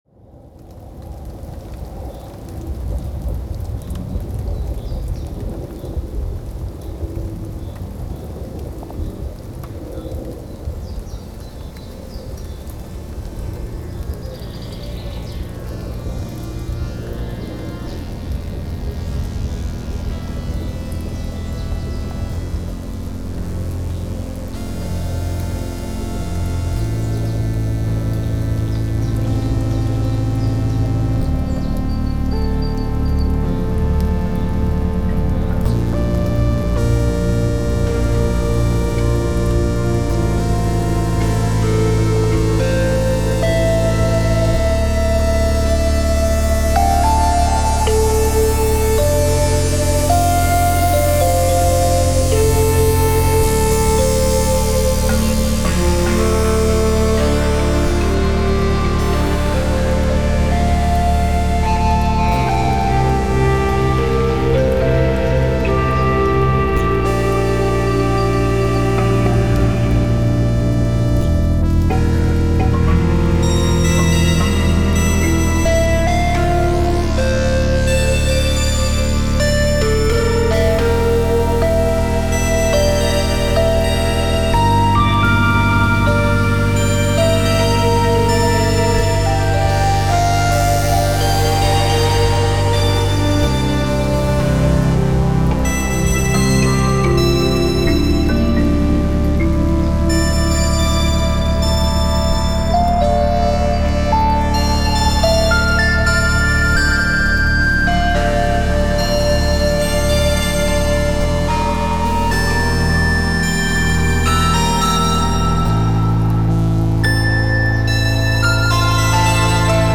Expanse is a generative music composition, meaning that the music is created in real-time by a system as it plays, as opposed to being composed beforehand like traditional music.
The instruments include a synth bass, a harmonically rich pad, a sample-based synth blending samples of a flute instrument with samples of a mallet instrument, another sample-based synth playing the yangqin (a Chinese hammered dulcimer), and synthetic airy keys.
I play field recordings of rain, birds, and wind throughout, crossfading between textures at preset times. I also include two sections where percussion comes in — namely a gong and some metallic textures — at preset times playing preset patterns.
The piece starts with just the field recordings, then gradually more instruments fade in, to resemble the listener getting more and more lost in this environment, then eventually the instruments drop out one by one, leaving only the field recordings again at the end, to resemble the listener finding their way.
Contributing factors include occasional heavy distortion on the flute and mallet instrument, punchy attacks and fast rhythms on several instruments, and the powerful percussion.
The bass holds the root note of each chord until the chord changes.